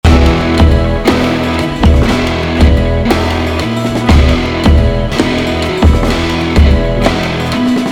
There’s an imperceptible little draaag where all the instruments slow down a tiny bit, and then they all race ahead to catch up to the beat.